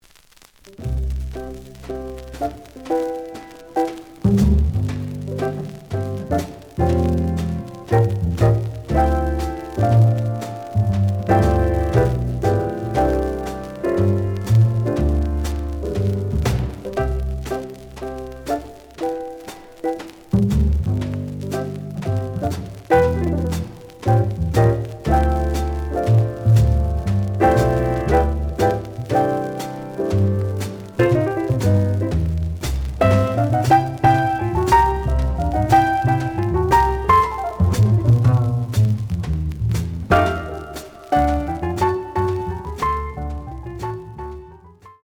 The audio sample is recorded from the actual item.
●Genre: Modern Jazz
Looks good, but slight noise on both sides.)